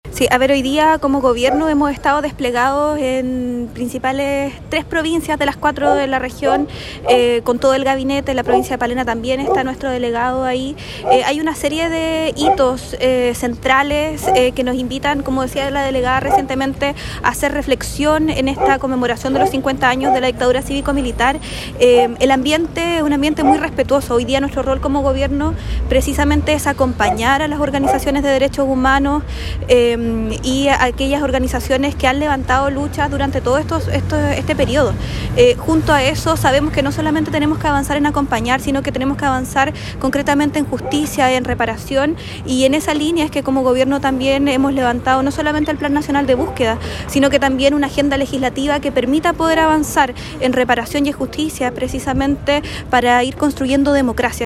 Por otra parte, la Seremi de Gobierno en Los Lagos, Danitza Ortiz, señaló que en la Región se desplegaron en las distintas comunas para participar de los actos conmemorativos, para acompañar a las organizaciones de los derechos humanos.